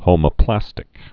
(hōmə-plăstĭk, hŏmə-)